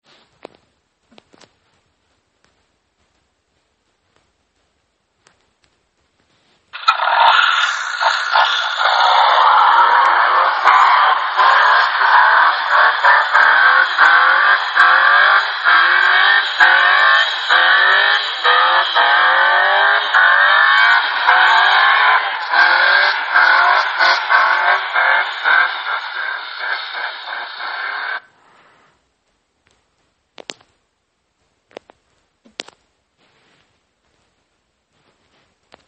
Die Feierabendfahrt nach Altenberg hat sich definitiv gelohnt! Mit den schlappen geht der Wagon noch besser quer...
Joar schreddermaschine trifft es sehr gut :D